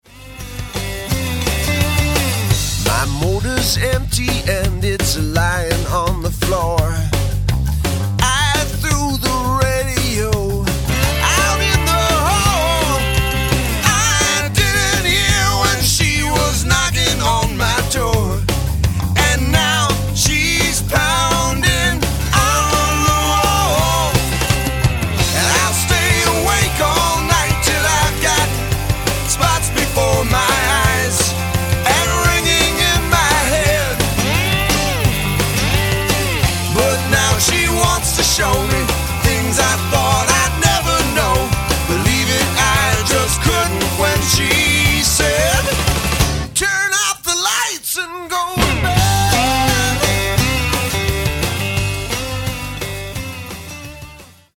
Acoustic & Electric Guitars, Vocals
Keyboards, Accordion